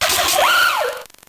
contributions)Televersement cris 5G.